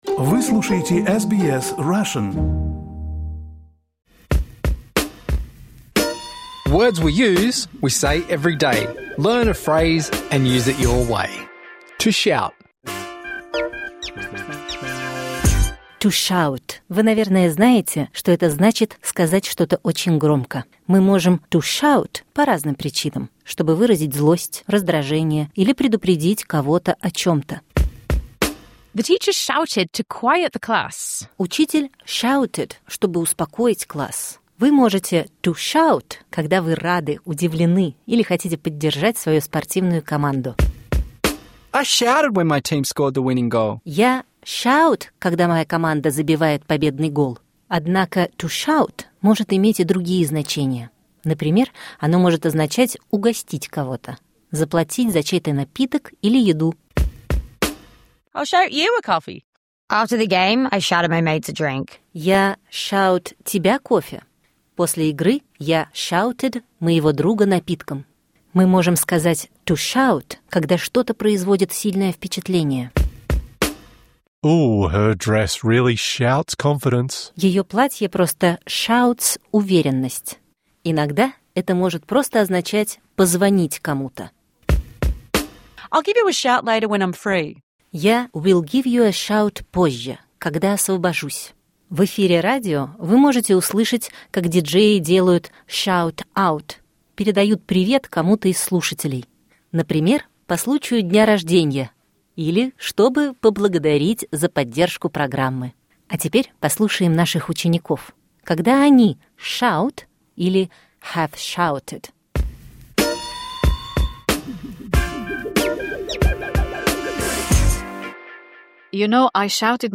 Words We Use — это двуязычная серия, которая помогает понять такие идиомы, как, например, «to shout».